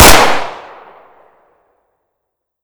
Tommy Gun Drop